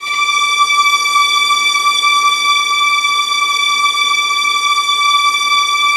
VIOLINS EN6.wav